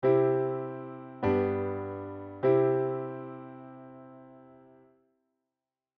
これを聞けば教室の風景が頭に浮かぶぐらい印象の強いコード進行だと思います。
使用しているコードは C – G – C で、キーはCメジャー。
特に、VからIへの進行はそのキーの中でも一番強いコード進行であり、そのキーのIのコードに帰って落ち着く感じがします
I-V-I.mp3